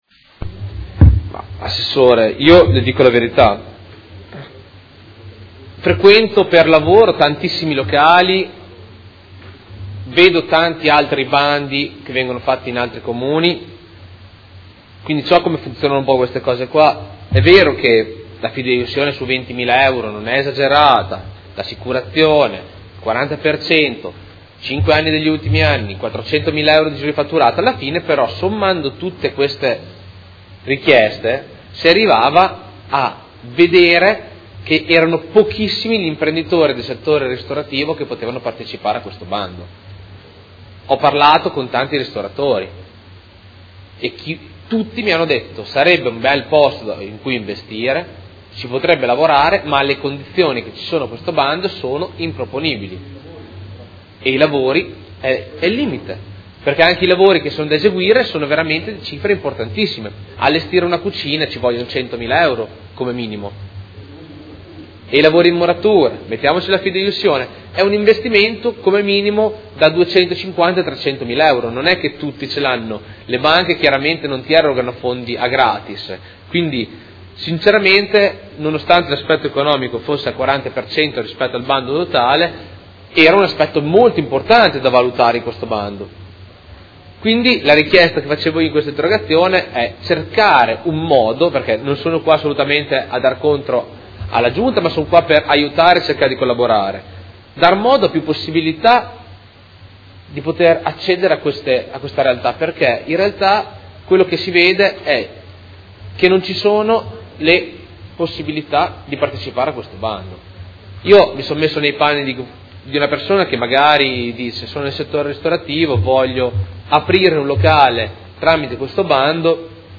Luca Fantoni — Sito Audio Consiglio Comunale
Seduta del 31/03/2016. Interrogazione del Gruppo Consiliare Movimento 5 Stelle avente per oggetto: Bando San Paolo. Replica